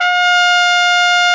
BRS CORNET08.wav